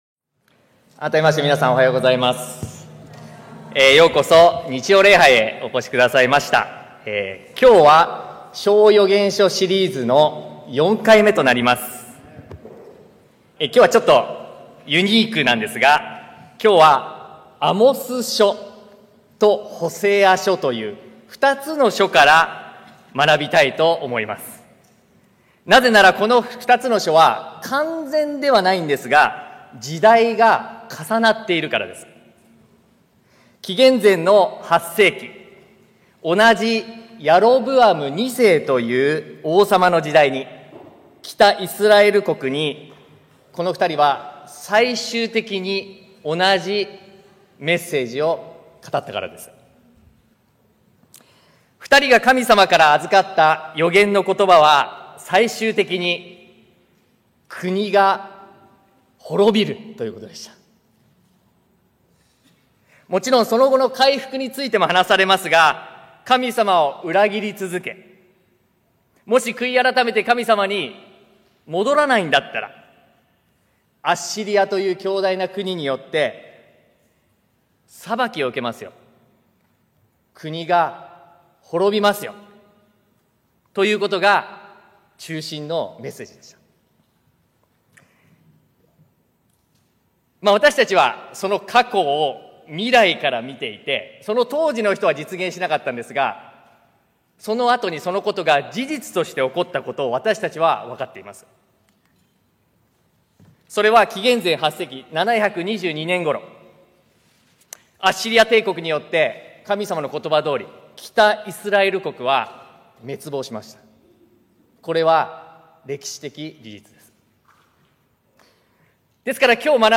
日本ジュビリー 保護者クラス(小4以上)